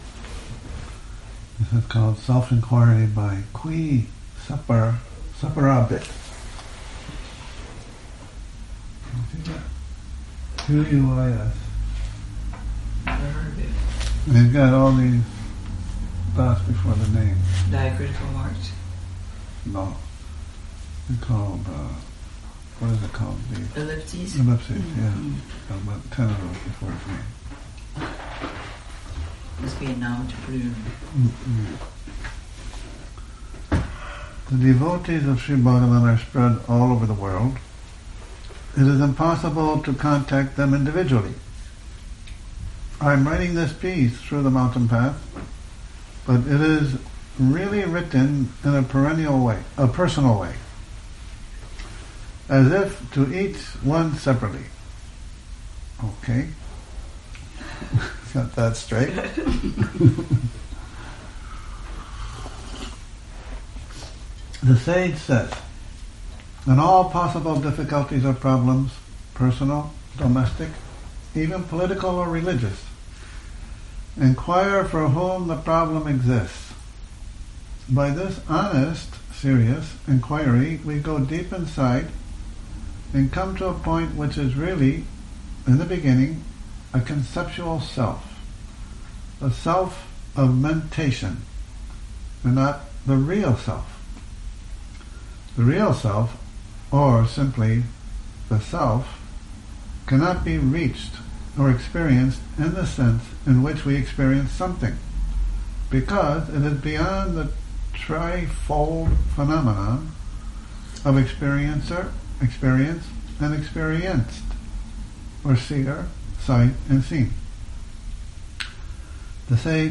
Morning Reading, 12 Nov 2019
this morning's reading starts with 'Self-Enquiry' by Quis Separabit April 1996, pg.194 lots of friendly banter and story-telling